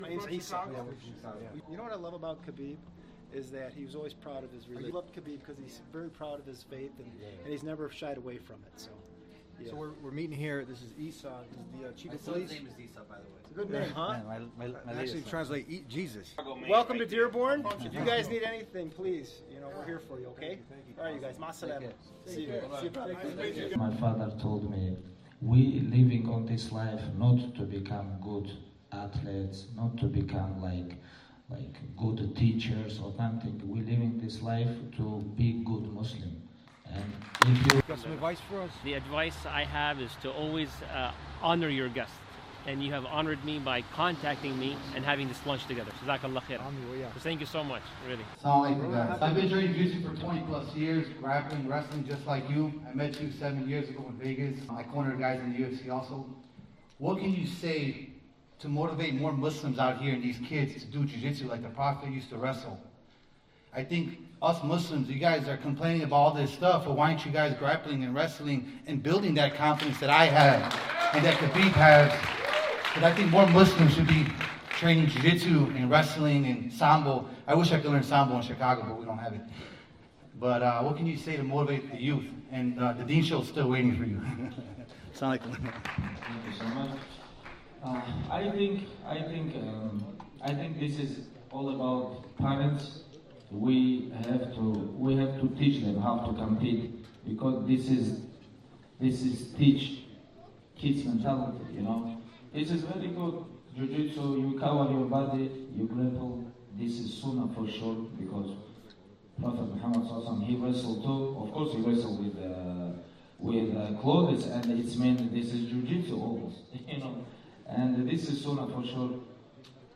In this memorable gathering, Khabib shares the profound wisdom his father instilled in him from a young age.